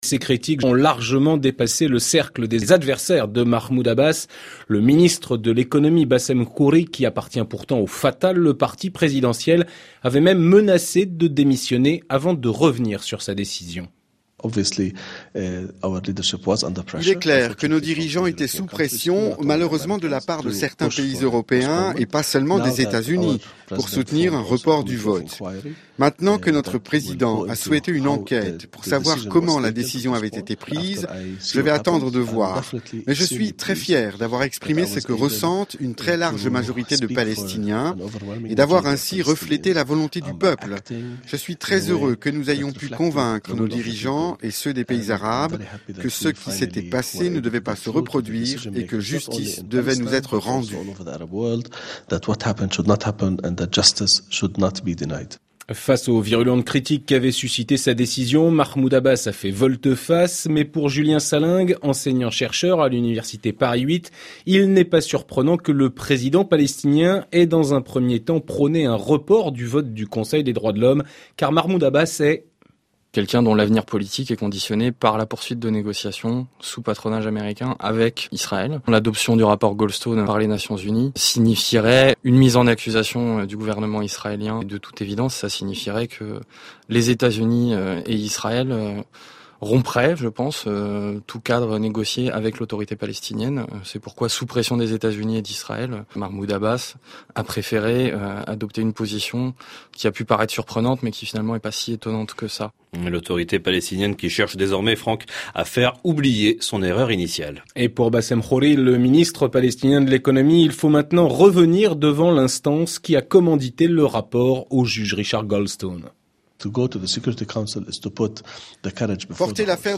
L’entretien radiophonique (au format mp3)
Entretien radiophonique diffusé sur Radio france International, édition du 12 octobre 2009.